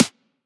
edm-snare-60.wav